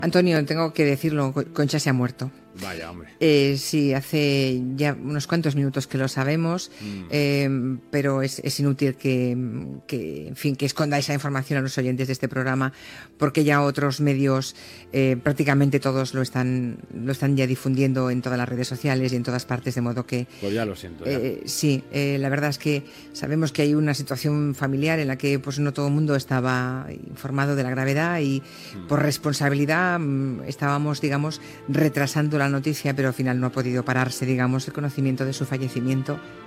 Julia Otero dona la notícia de la mort de la periodista Concha García Campoy.
Entreteniment